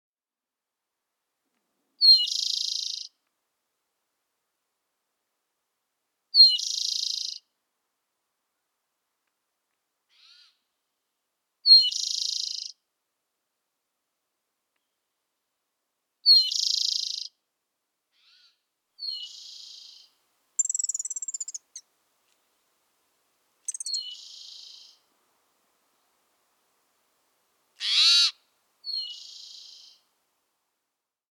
Spotted Towhee
How they sound: Their song starts with a few short introductory notes and is followed by a fast trill that sounds like a piece of paper stuck into a fan.